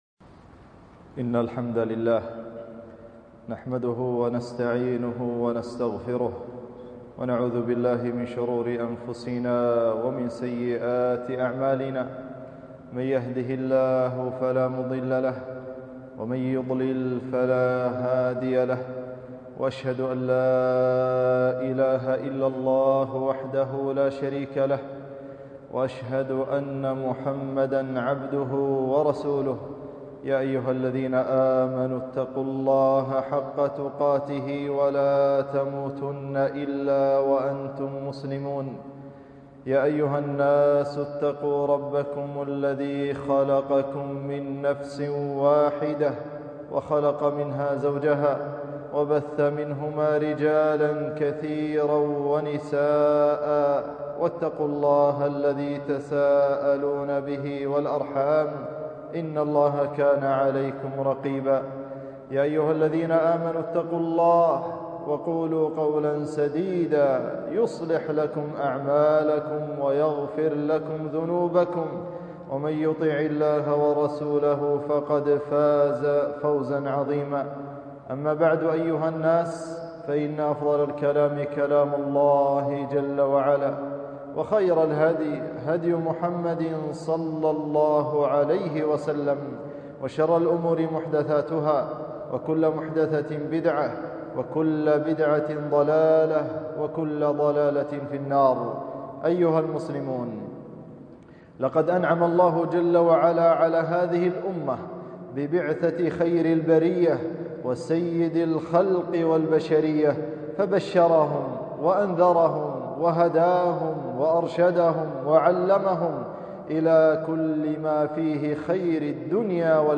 خطبة - مكانة السنة ووجوب العمل بها